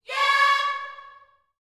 YEAH C#5E.wav